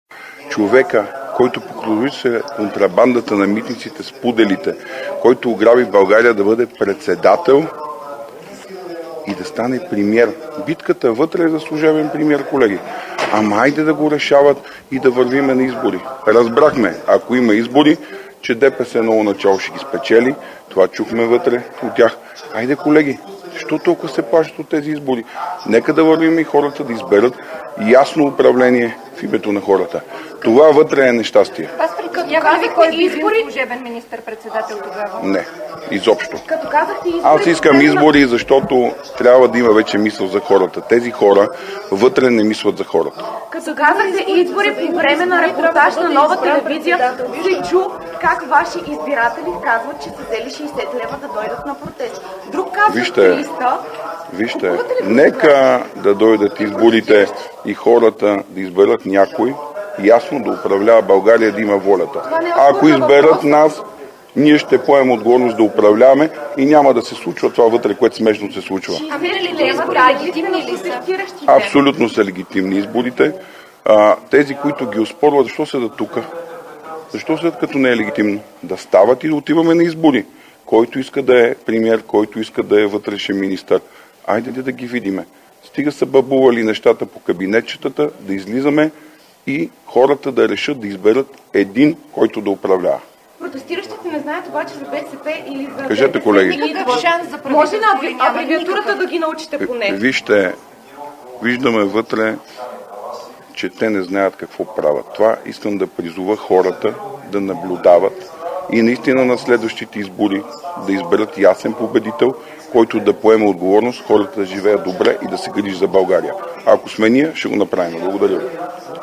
11.45 - Брифинг на съпредседателя на ДПС Делян Пеевски. - директно от мястото на събитието (Народното събрание )